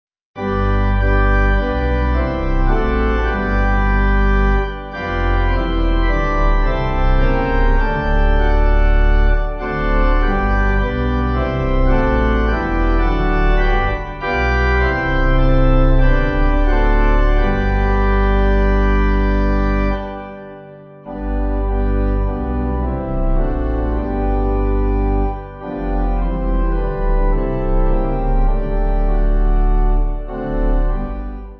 (CM)   4/G